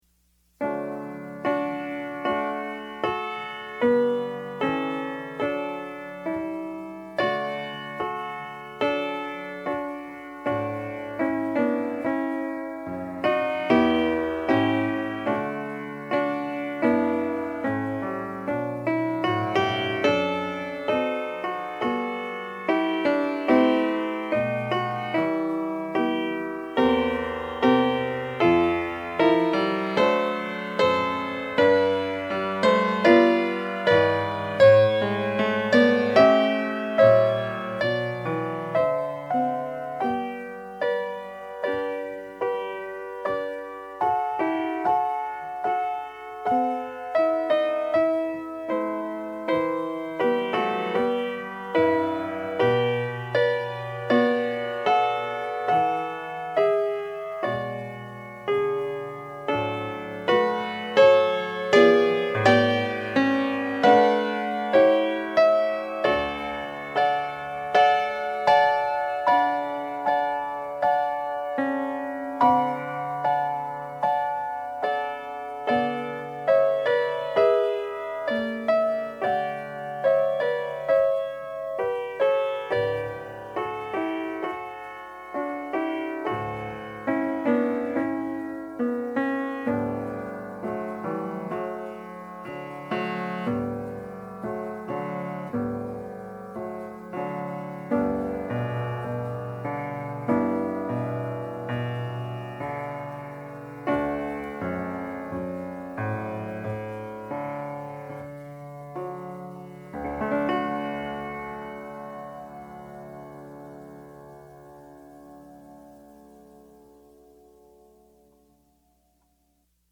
PIANO SOLO Sacred Music, Piano Solo, Prayer, & Interlude
DIGITAL SHEET MUSIC - PIANO SOLO